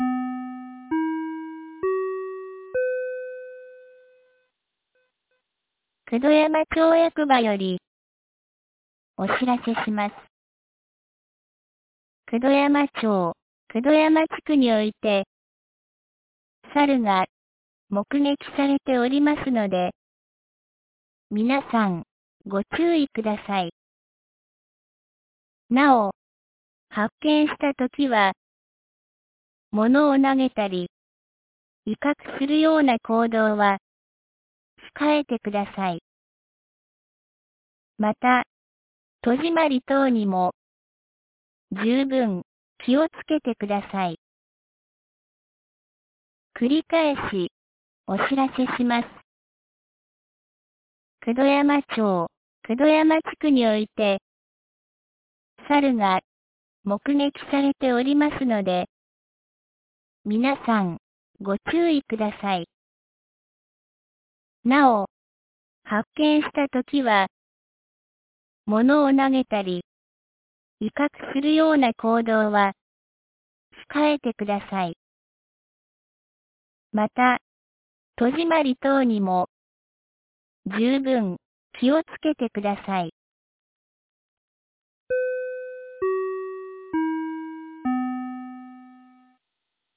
2026年04月01日 15時41分に、九度山町より九度山地区、入郷地区、慈尊院地区へ放送がありました。
放送音声